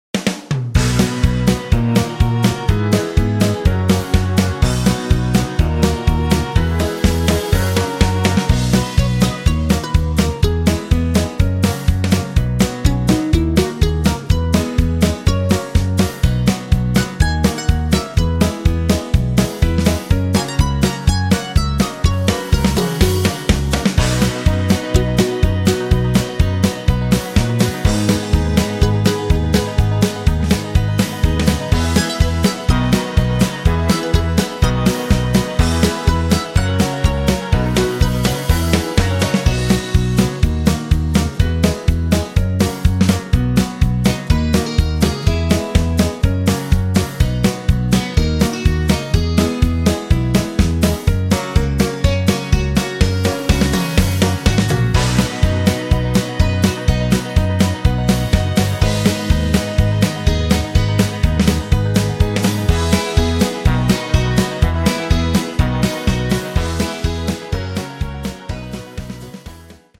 Square Dance Music
Music sample